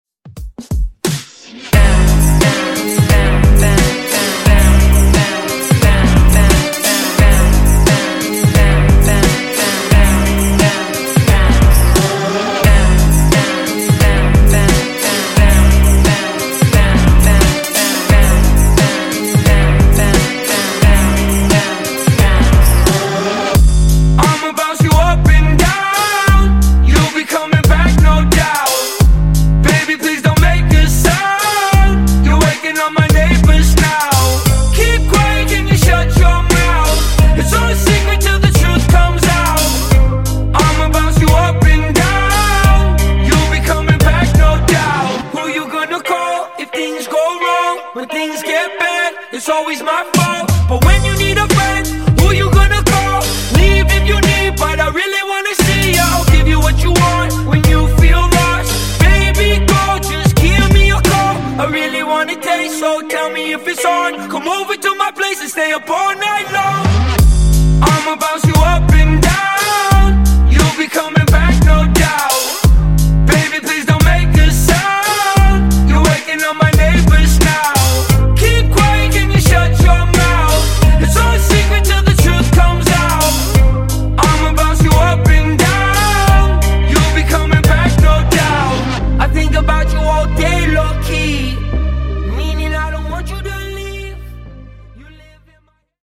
Genres: DANCE , TOP40
Dirty BPM: 124 Time